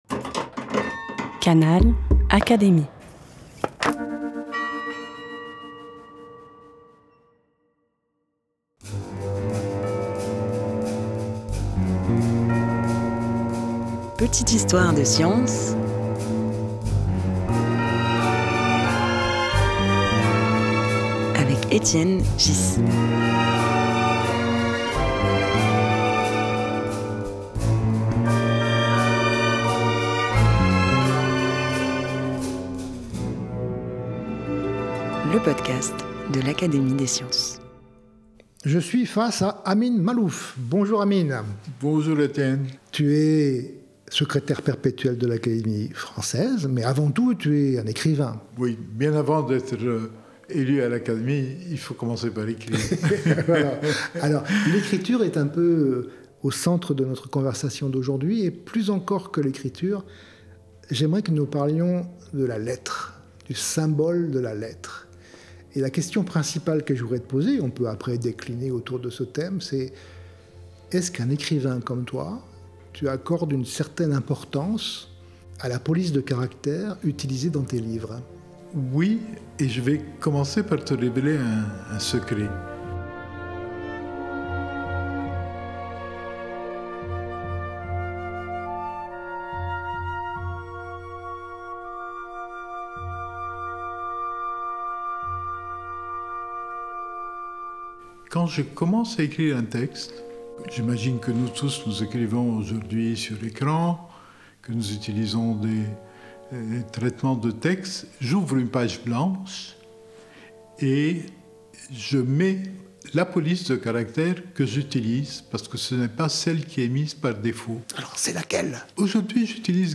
Étienne Ghys s’entretient avec Amin Maalouf, Secrétaire perpétuel de l’Académie française et grand écrivain des langues et des civilisations. Ensemble, ils évoquent la beauté du Garamond, les secrets des alphabets, et la manière dont chaque langue (arabe, française et anglaise) façonne une manière singulière de penser le monde.